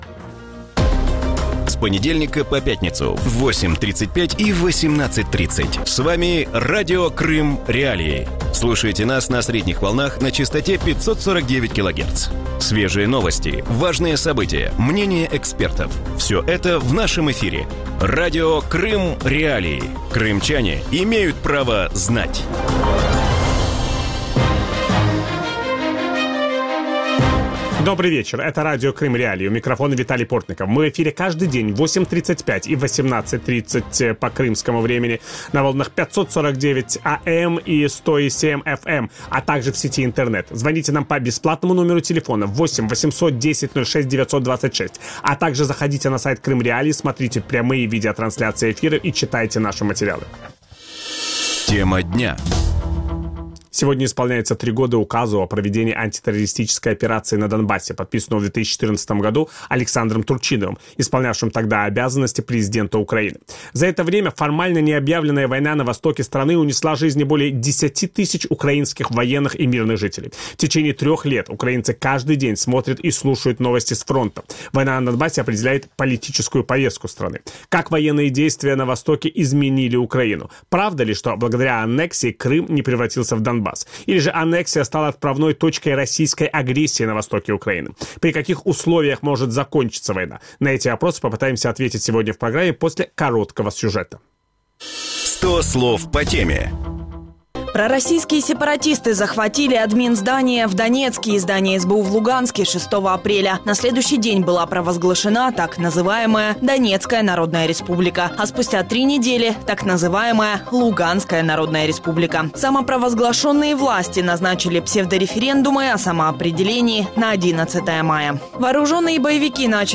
У вечірньому ефірі Радіо Крим.Реалії обговорюють війну на Донбасі. Як змінюється Україна після трьох років з дня оголошення антитерористичної операції на сході країни? Скільки ще триватиме конфлікт і чим він може закінчитися?